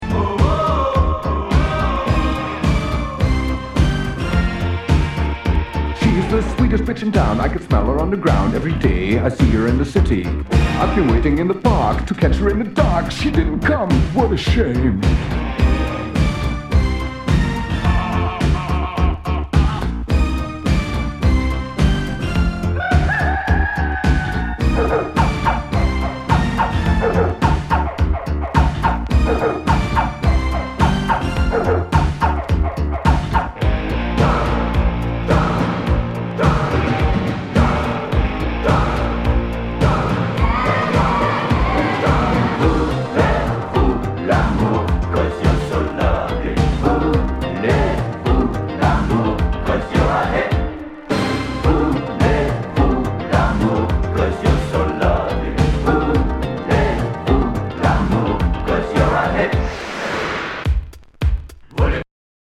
SOUL/FUNK/DISCO
ナイス！ユーロ・シンセ・ポップ・ディスコ！
全体にチリノイズが入ります